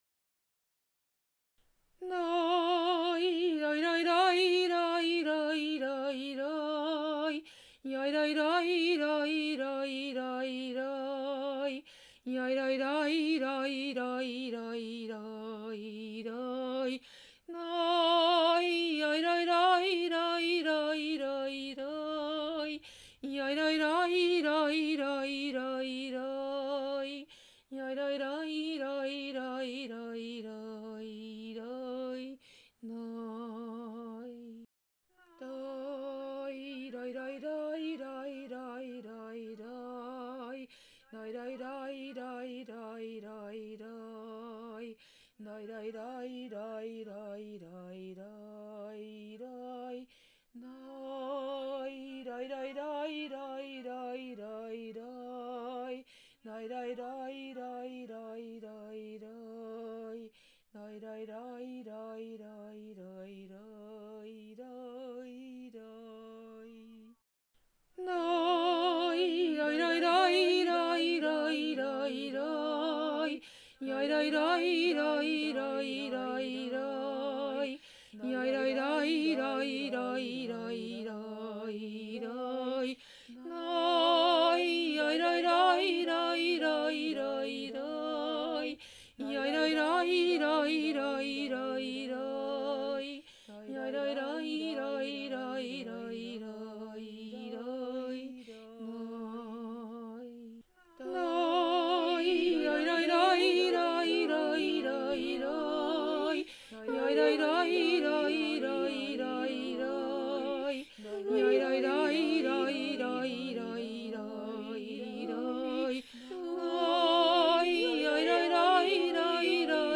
ubechen_niggun_mlt.mp3